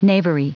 Prononciation du mot knavery en anglais (fichier audio)